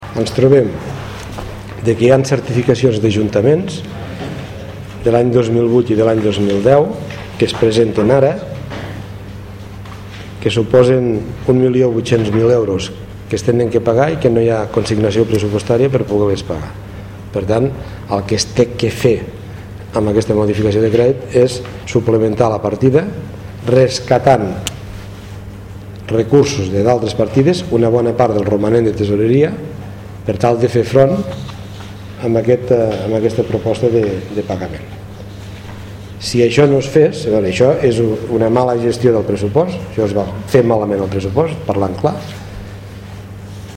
Tal com ha explicat el president de la Diputació, Joan Reñé, en la roda de premsa posterior al Ple, la Corporació també ha aprovat una modificació de la distribució plurianual del PIL, anualitats 2008-2012, per tal d’agilitzar aquests pagaments, millorant així la liquiditat d’aquests.